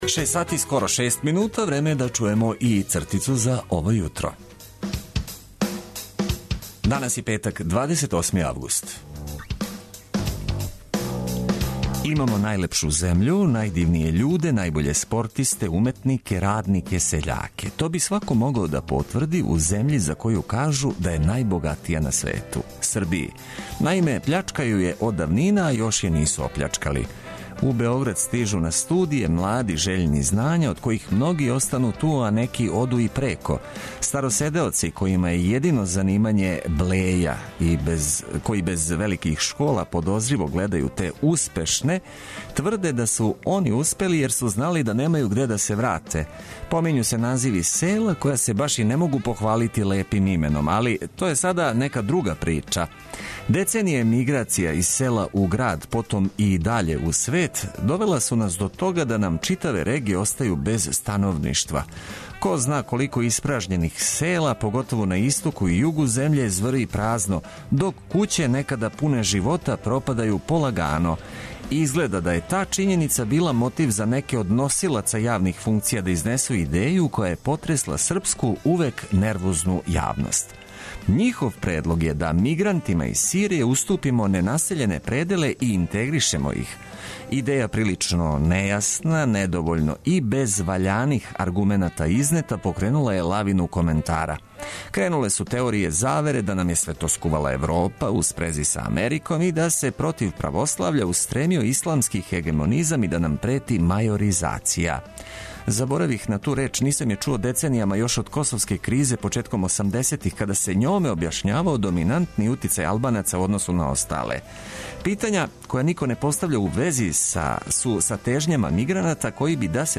Уз ауторски текст Цртица 202 почећемо емисију и позвати вас да шаљете коментаре.